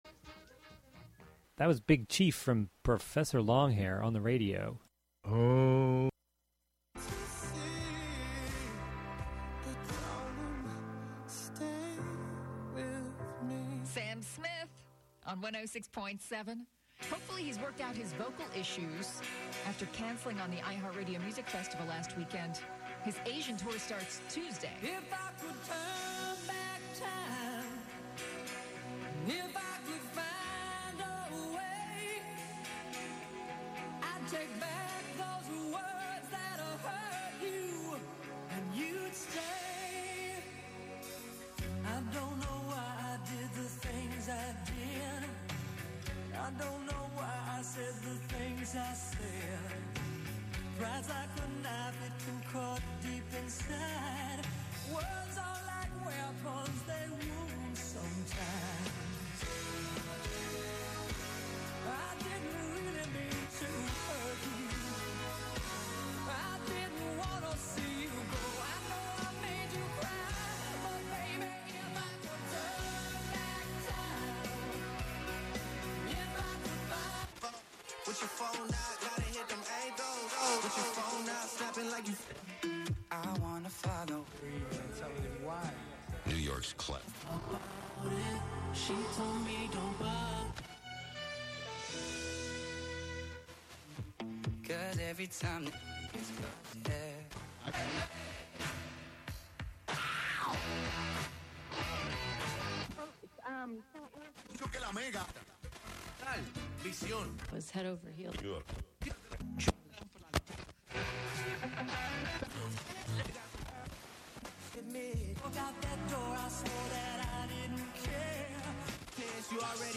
Live from Brooklyn, NY
making instant techno 90% of the time